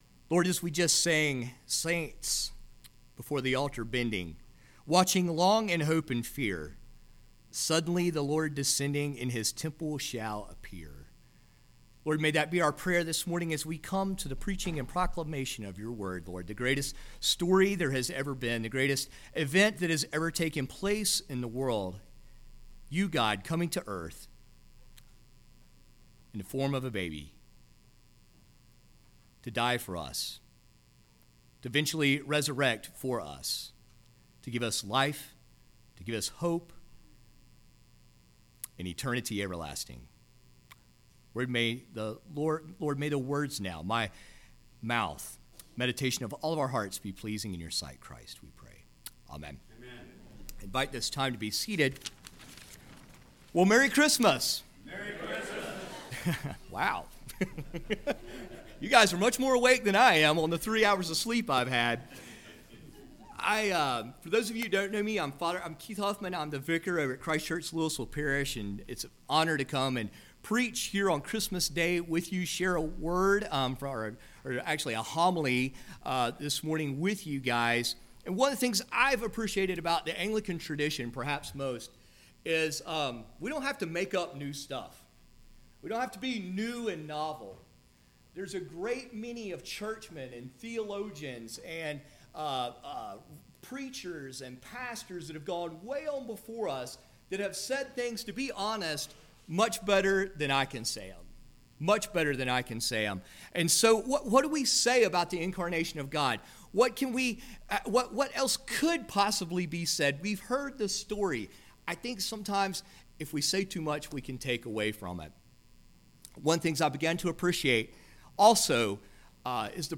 Christmas Homily